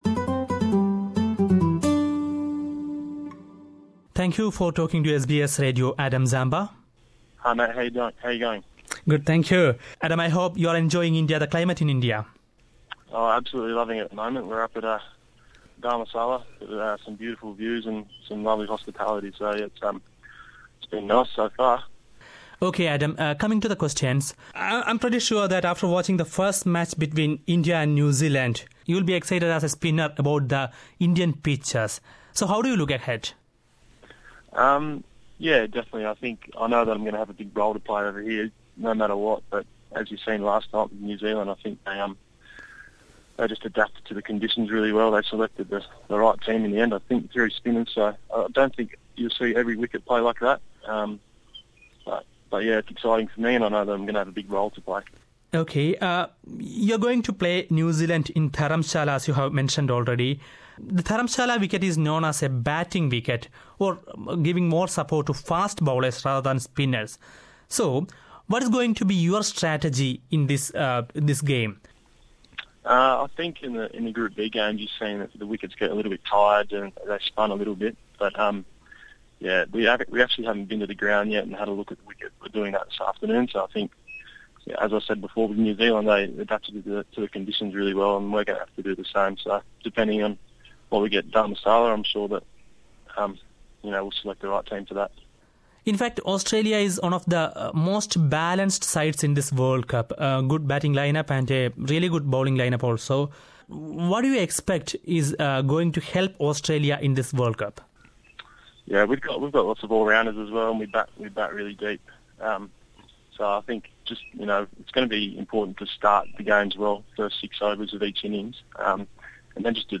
Adam Zampa, the young leg spinner from New South Wales, talked to SBS Radios Malayalam language program ahead of the match. Adam says that he is eagerly waiting to bowl against the subcontinent batsmen, especially Indias Virat Kohli. Listen to the interview here.